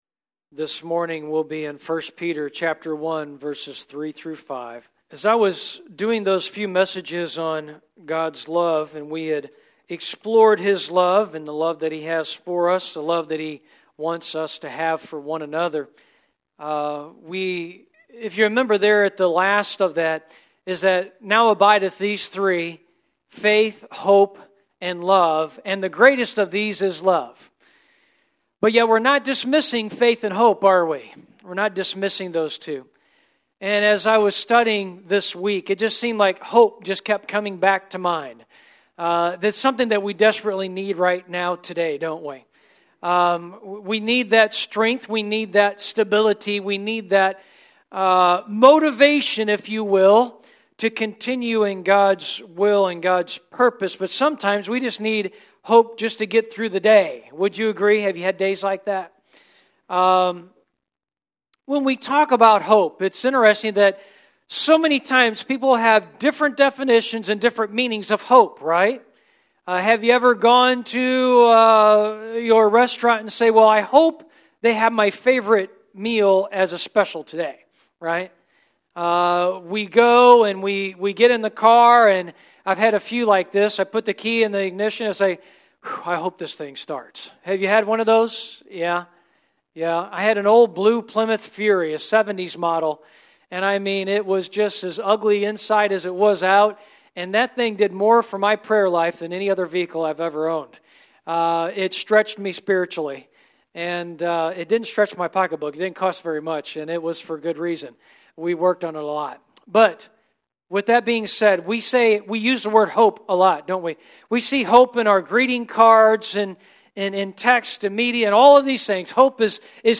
Our Weekly Sermons Jan-Dec 2021